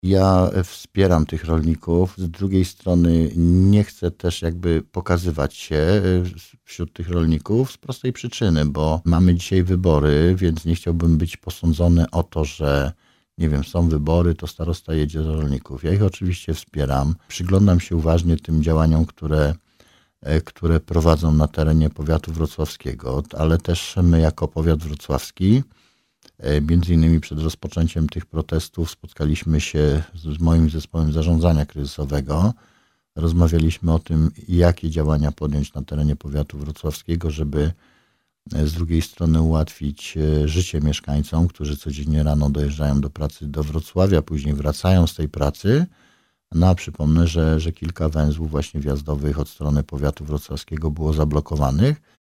O bieżącej sytuacji i ocenie strajku rolników mówi starosta Roman Potocki.